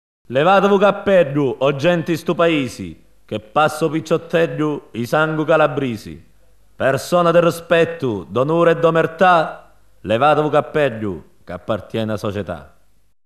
Жанр: Mafia Folk